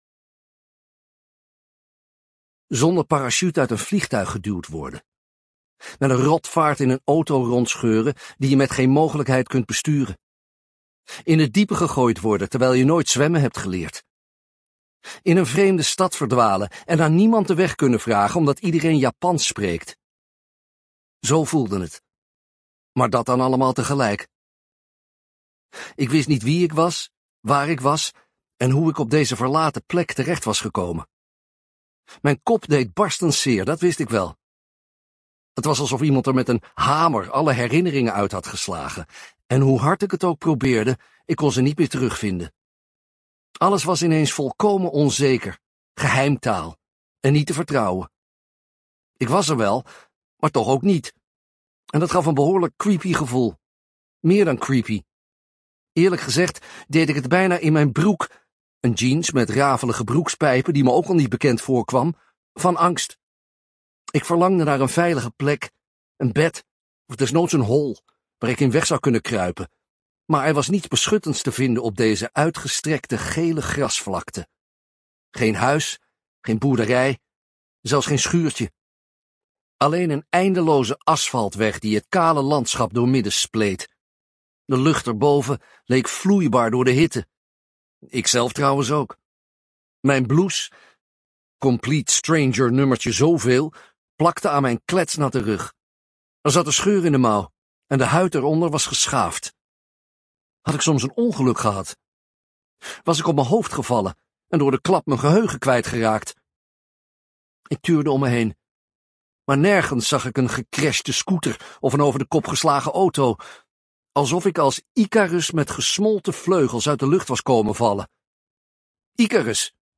boy7audioboek.mp3